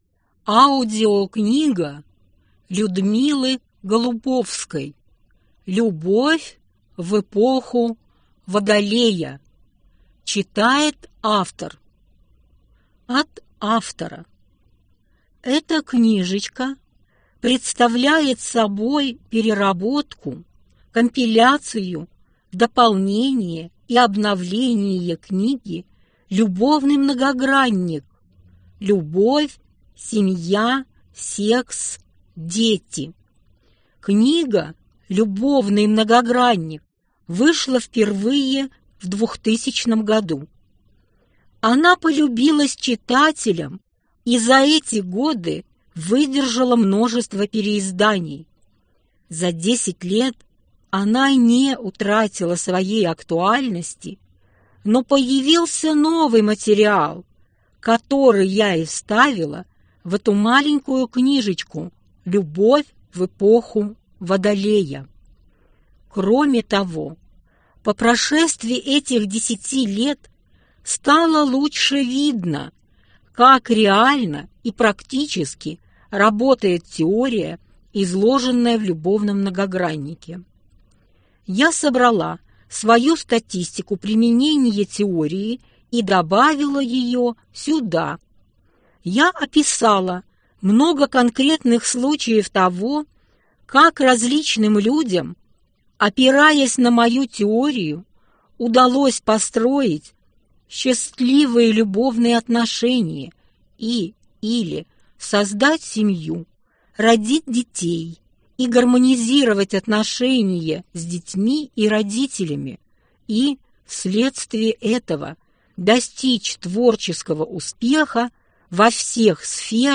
Аудиокнига Любовь в эпоху Водолея | Библиотека аудиокниг